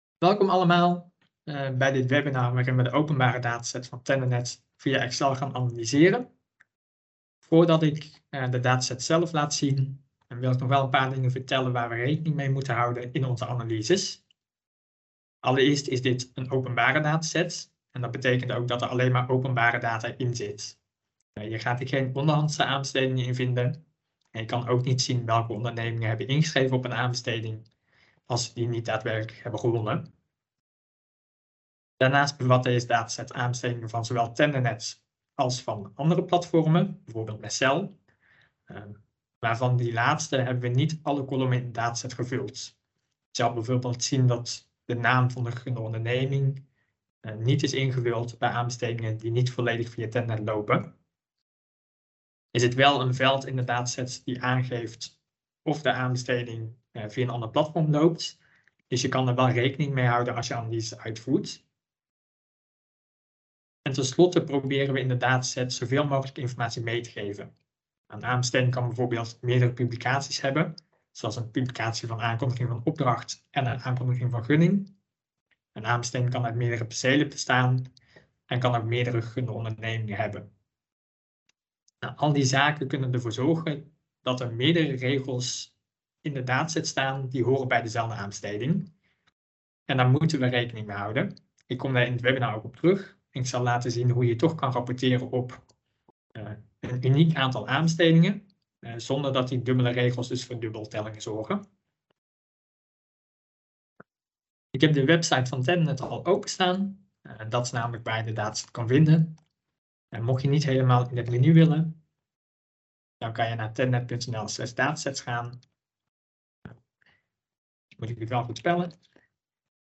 Video webinar: Aan de slag met aanbestedingsdata | TenderNed